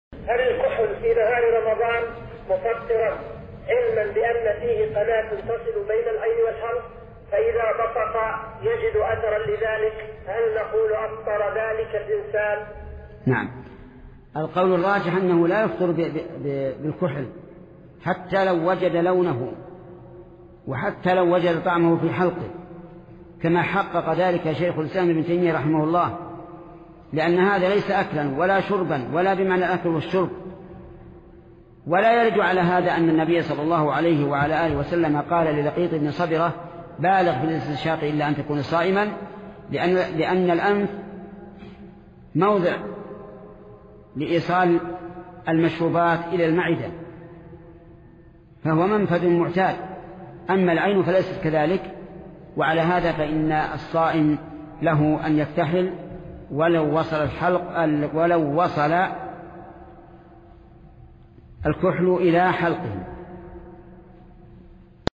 الفتاوى  |  هل استعمال الكحل في نهار رمضان يؤثر على صحة الصوم؟
محمد بن صالح العثيمين